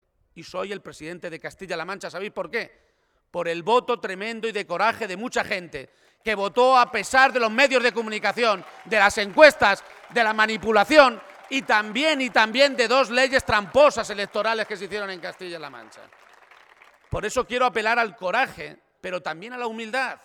En un acto público celebrado en la localidad toledana de Yuncos, Emiliano García-Page aseguraba que “soy presidente de Castilla-La Mancha por el voto tremendo y de coraje de mucha gente que votó a pesar de los medios de comunicación, de las encuestas, de la manipulación y también de dos leyes tramposas electorales que se hicieron en Castilla-La Mancha”, por eso, añadía, “quiero apelar al coraje y también a la humildad”.
Cortes de audio de la rueda de prensa